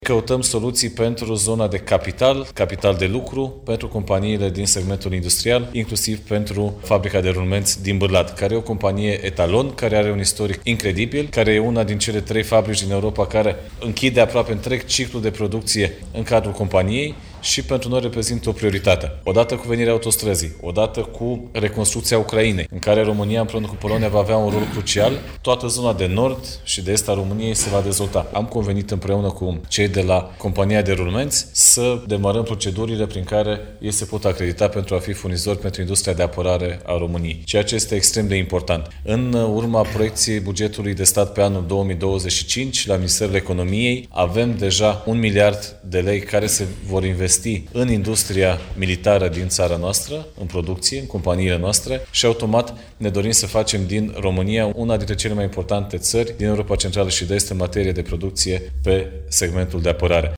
Aflat, astăzi, în vizită la Fabrica de Rulmenţi din Bârlad, acesta a subliniat importanța acreditării companiei, pentru a putea fi furnizor în industria de apărare.